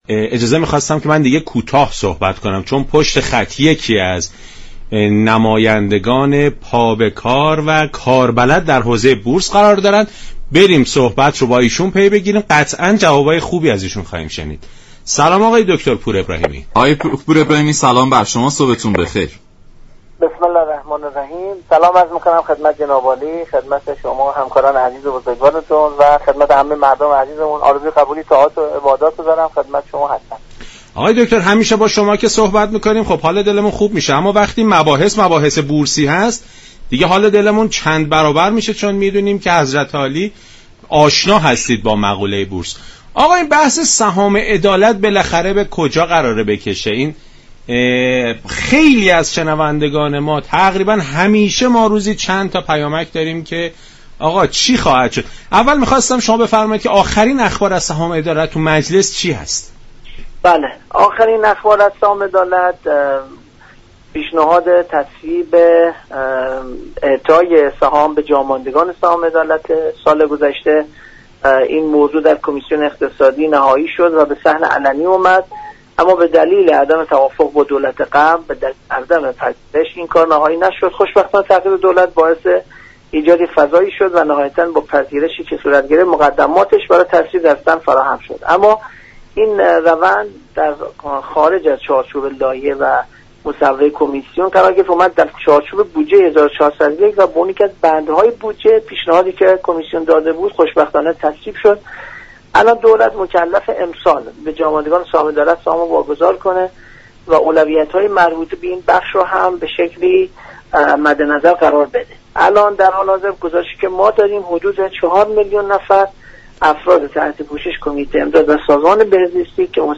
به گزارش شبكه رادیویی ایران، «محمدرضا پور ابراهیمی» رئیس كمیسیون اقتصادی مجلس در برنامه «سلام صبح بخیر» رادیو ایران به بحث سهام عدالت پرداخت و گفت: مجلس در تازه ترین اقدام خود پیشنهاد تصویب اعطای سهام عدالت به جاماندگان این سهام داده است.